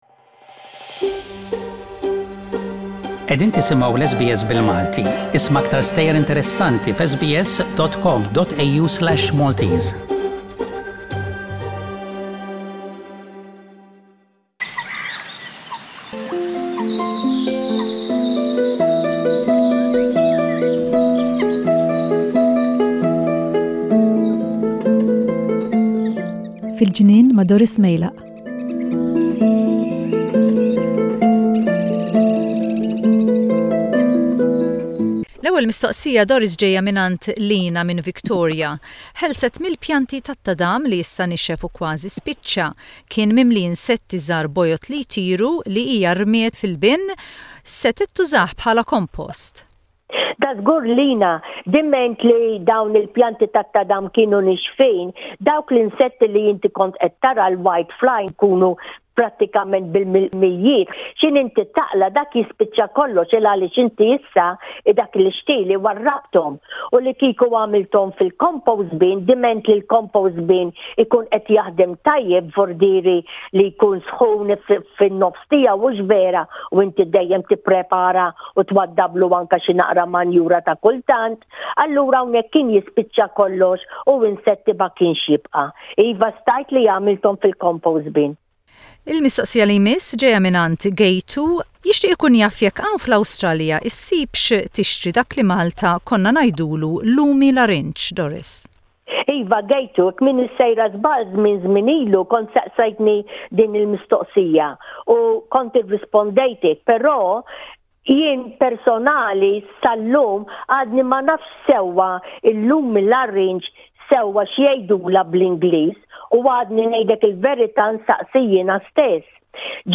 Gardening questions and answers